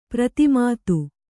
♪ prati mātu